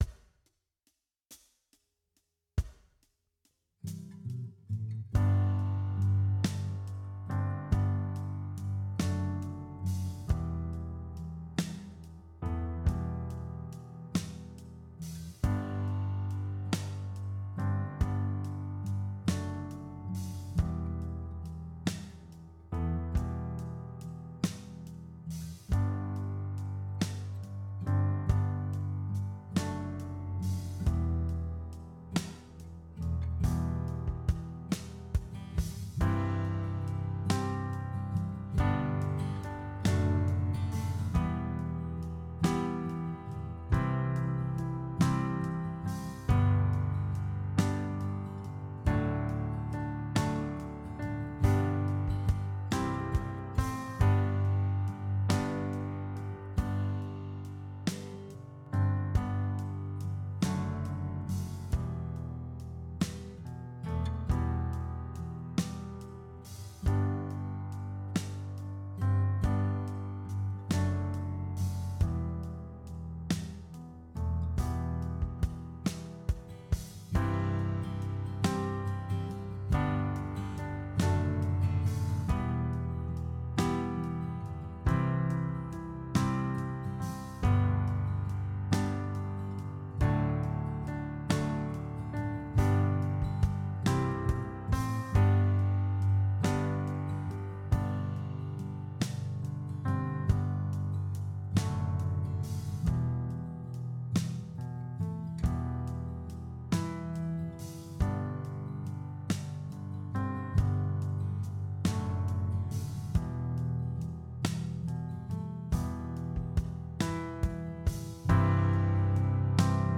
Jam Track